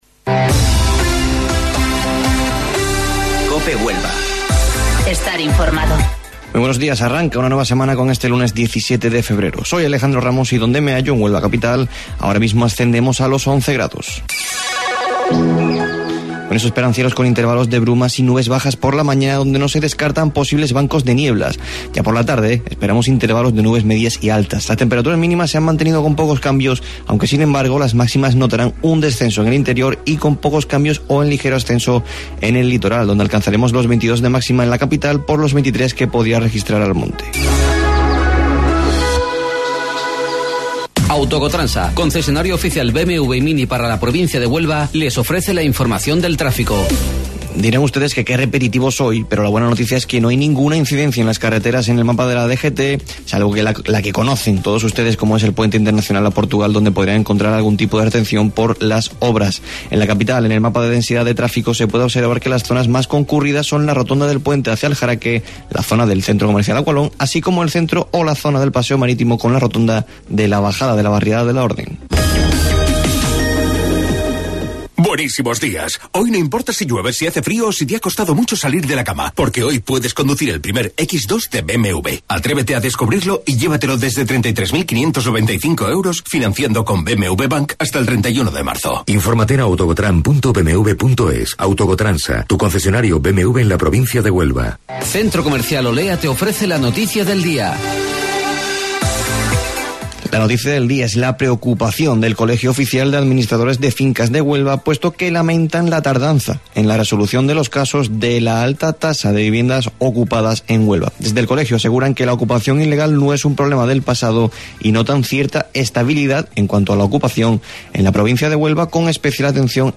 AUDIO: Informativo Local 08:25 del 17 de Febrero